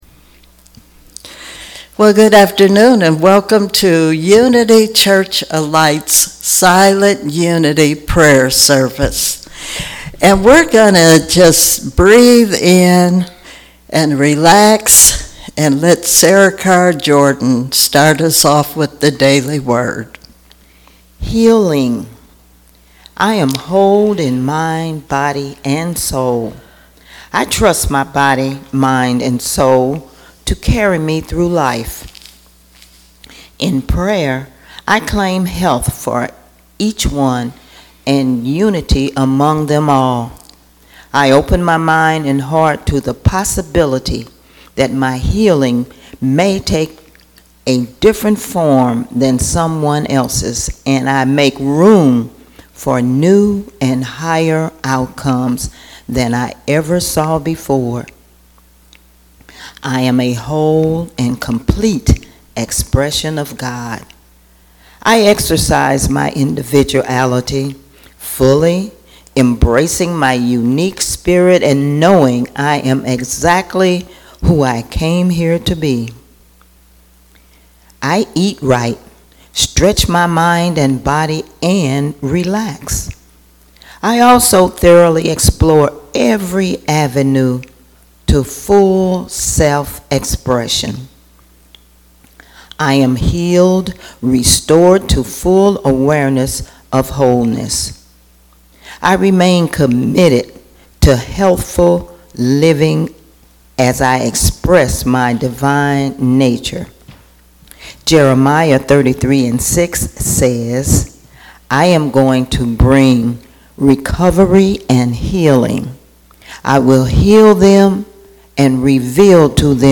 04/26/23 - Silent Unity Prayer Service